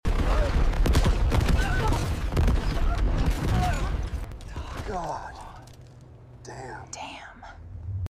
Blasters flying. She walks in slow motion like she owns the galaxy.